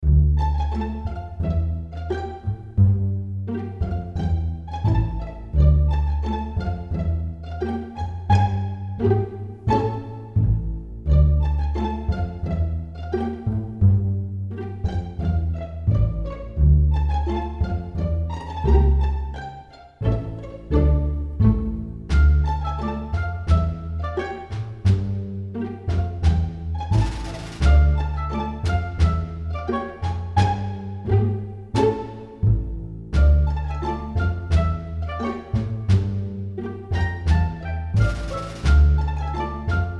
интригующие
загадочные , без слов , инструментальные